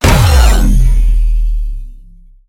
hurt1.wav